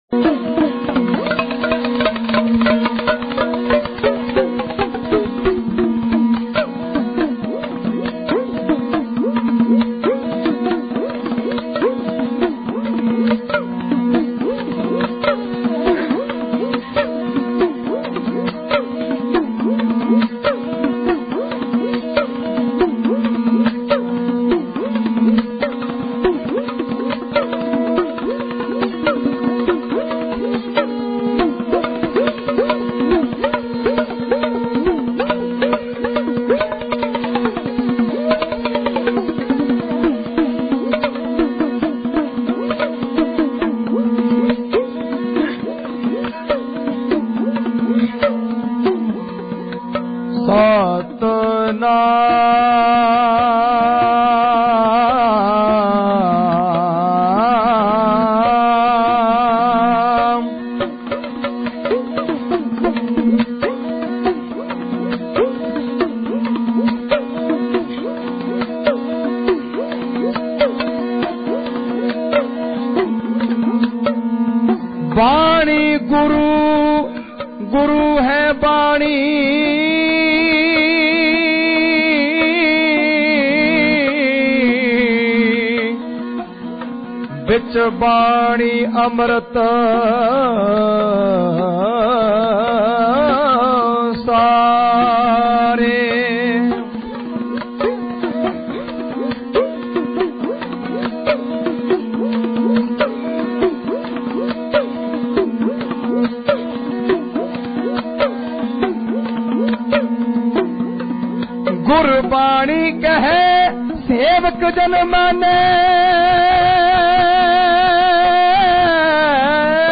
Genre: Dhadi Vaara Album Info